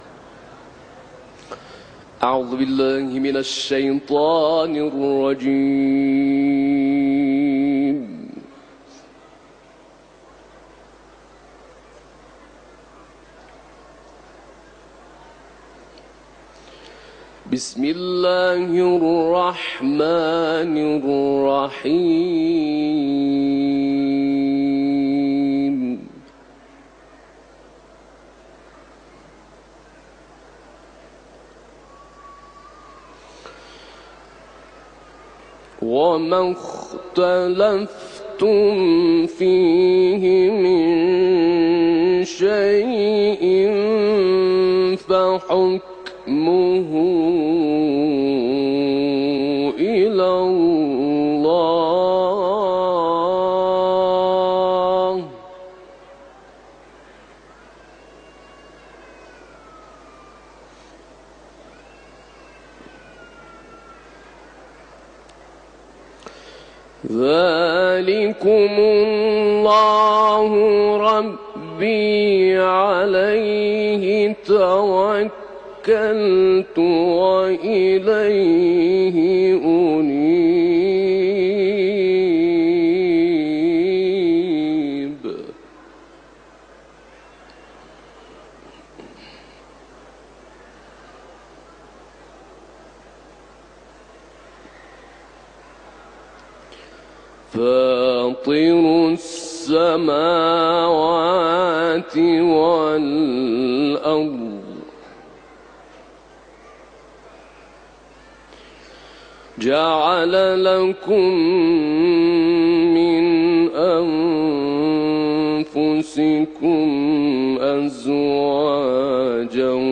теги: Сура «аш-Шура» ، Таляват